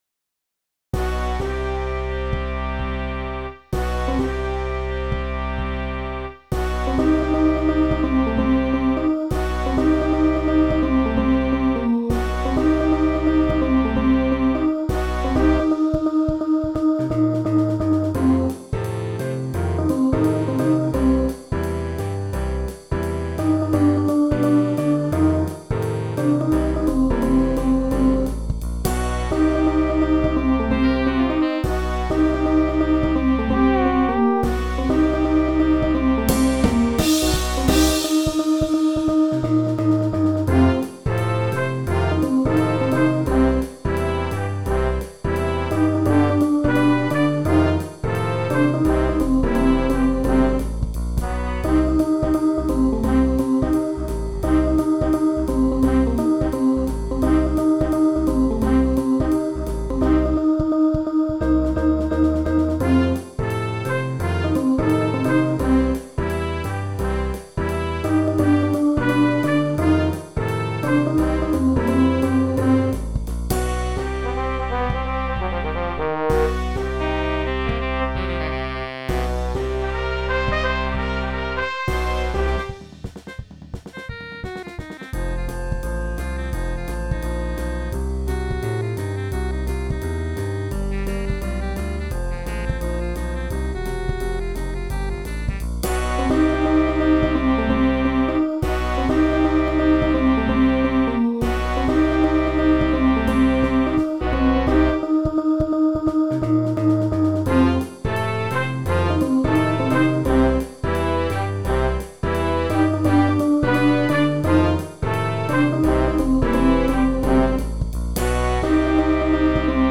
Rehearsal Files for Band Members
Jailhouse Rock (4 Horns Vocal)